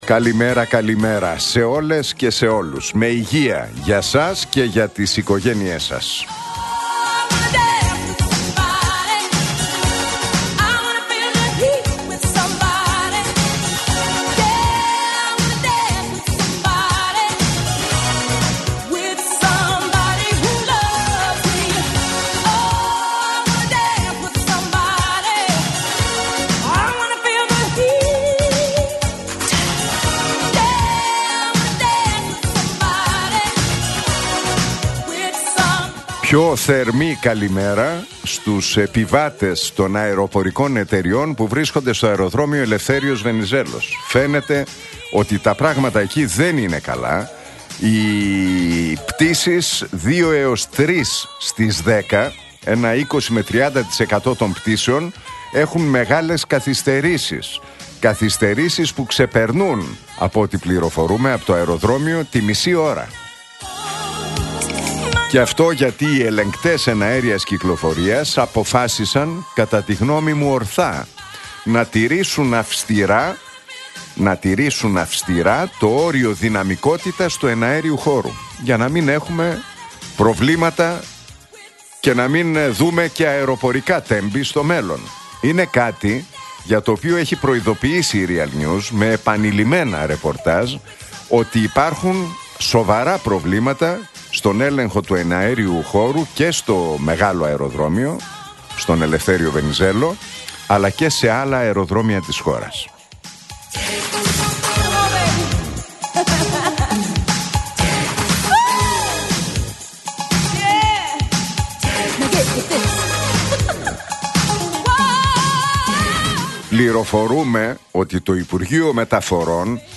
Ακούστε το σχόλιο του Νίκου Χατζηνικολάου στον ραδιοφωνικό σταθμό Realfm 97,8, την Τρίτη 30 Σεπτεμβρίου 2025.